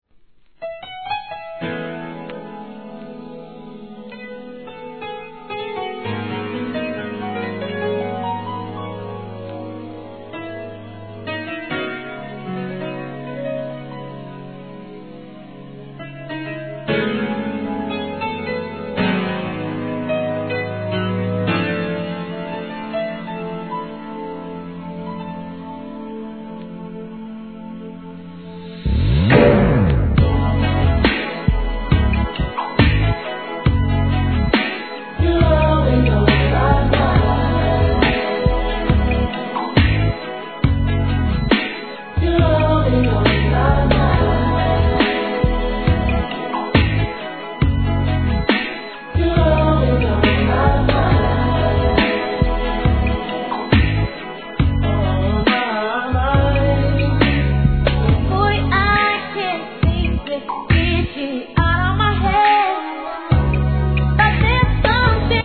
HIP HOP/R&B
清涼感溢れるヴォーカルワークが載るミディアムなR&Bナンバー!!